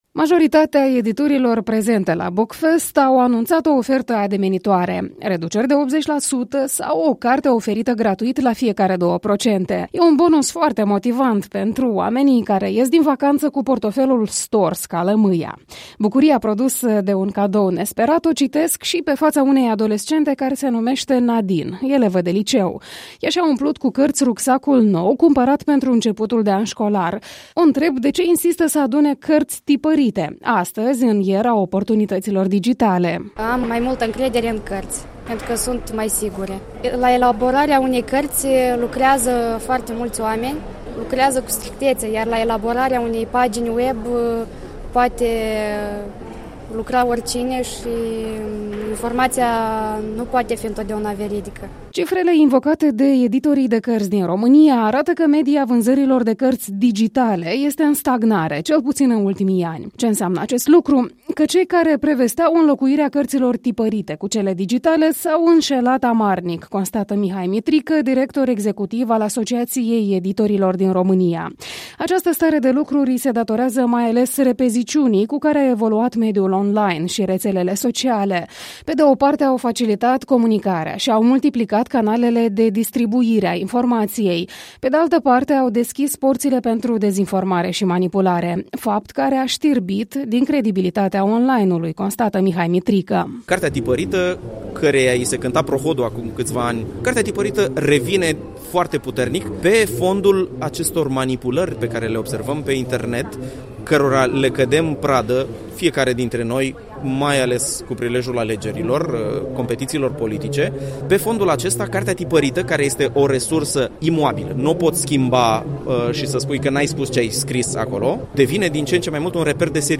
Reportaj de la târgul Bookfest la Chişinău